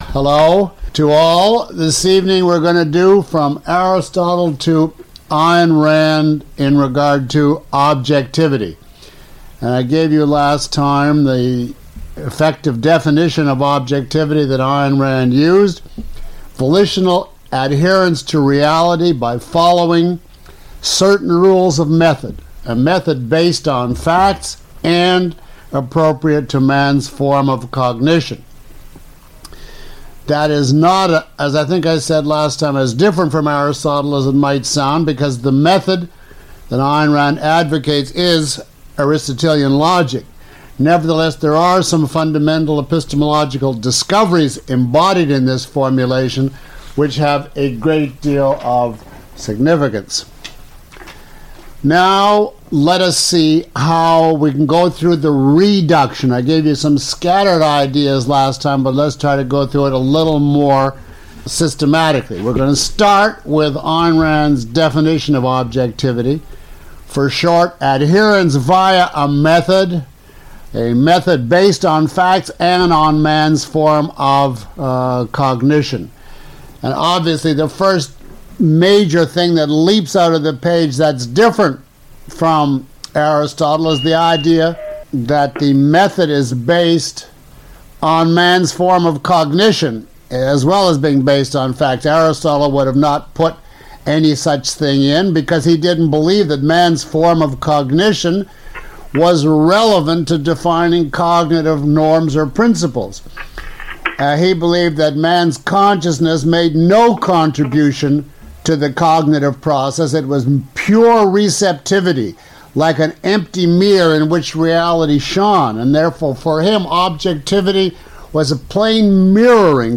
Lecture 07 - Objectivism Through Induction.mp3